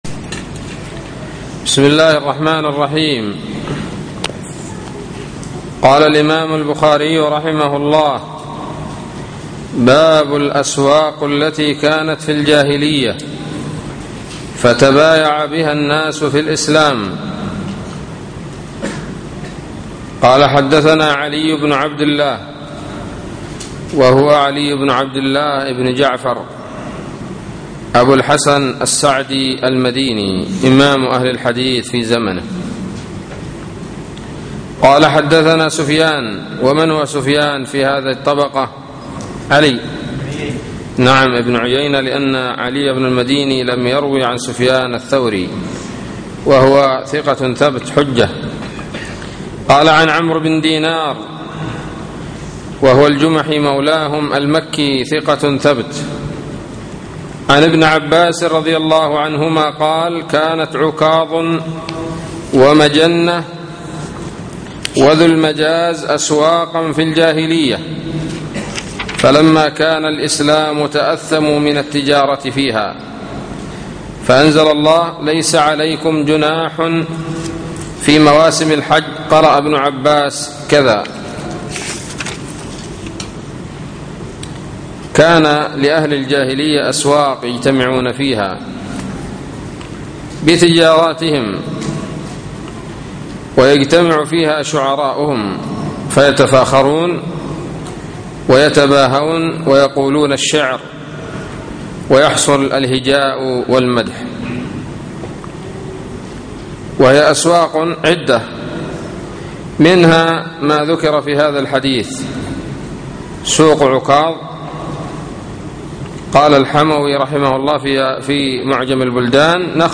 الدرس الواحد والثلاثون